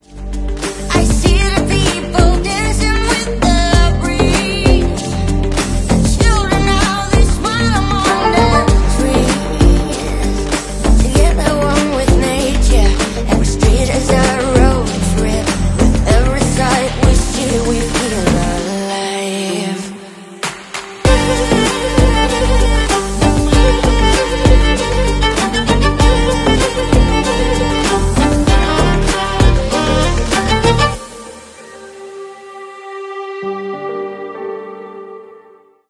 • Качество: 320 kbps, Stereo
Танцевальные